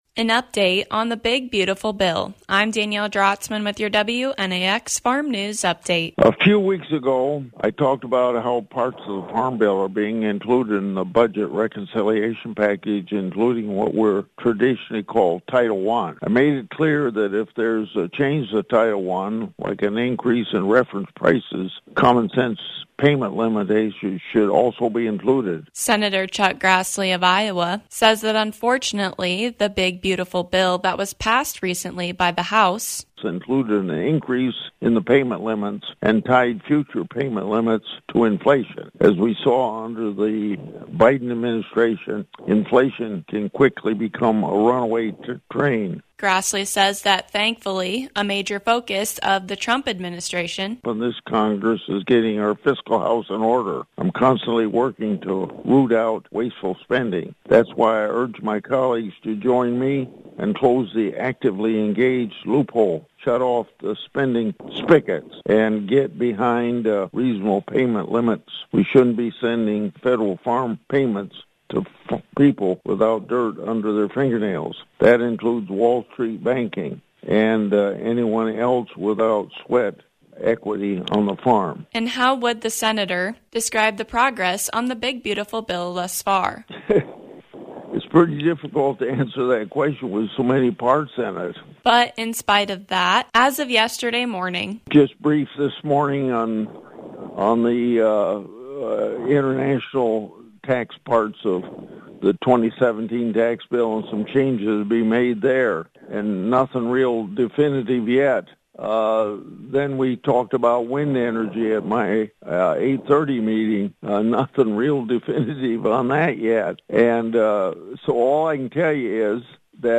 An update on the big, beautiful bill with Senator Chuck Grassley.